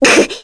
Rodina-Vox_Damage_kr_01.wav